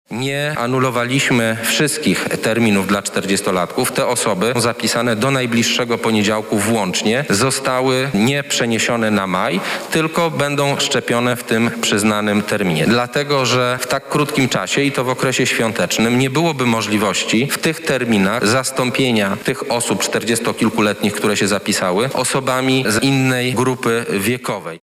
-mówi Szef Kancelarii Prezesa Rady Ministrów Michał Dworczyk.